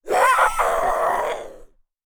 femalezombie_chase_02.ogg